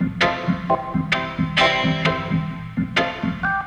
ORGANGRAT1-R.wav